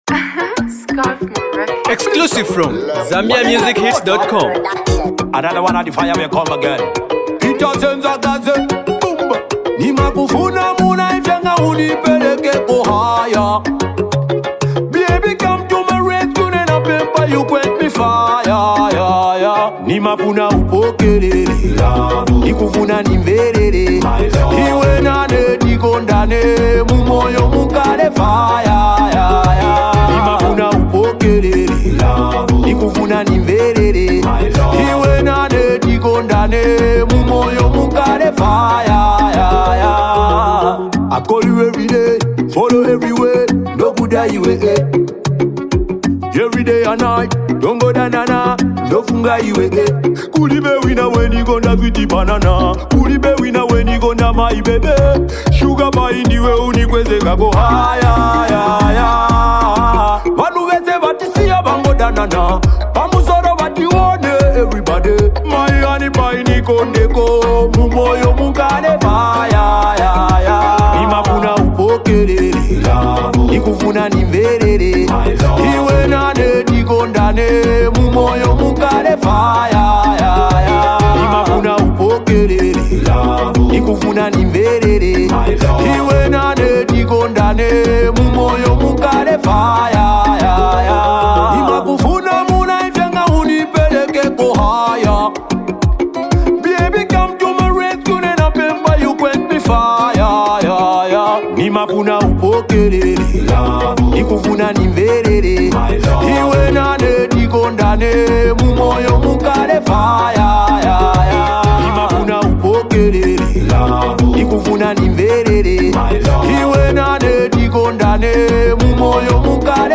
love themed Zimdancehall riddim